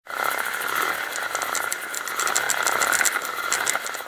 Reeling.ogg